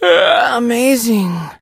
sandy_lead_vo_02.ogg